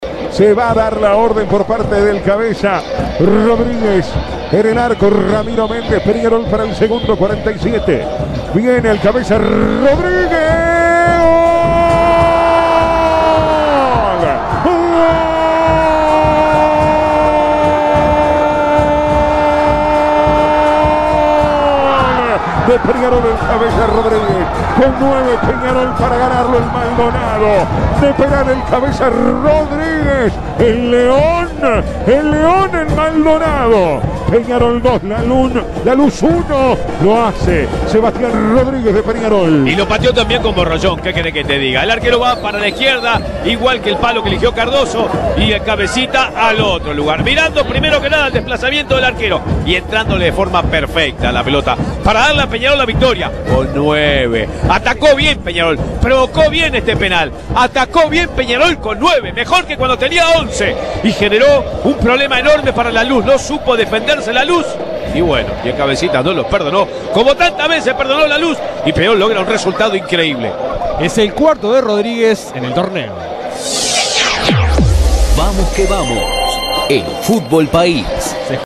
El partido de locos entre merengues y carboneros en ma voz del equipo de VQV